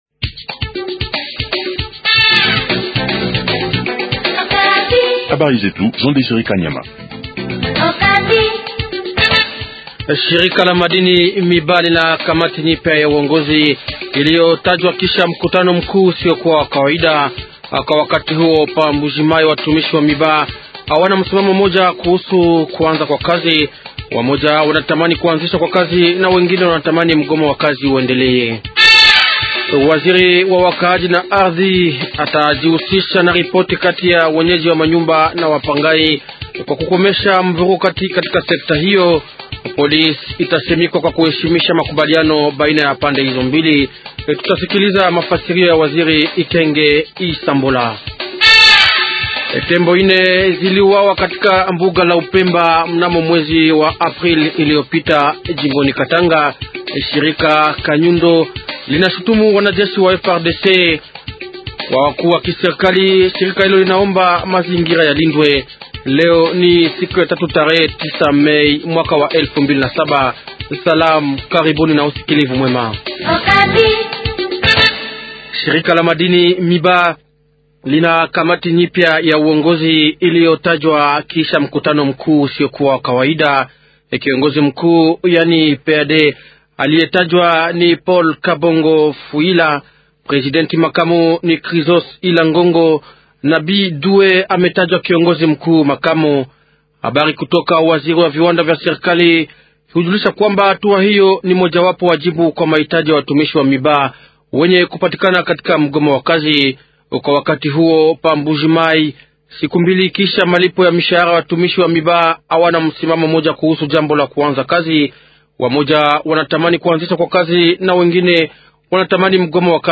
090507-journal swahili soir